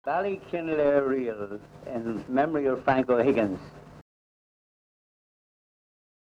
Ballykinler Reel
Irish Music